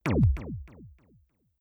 laser1.wav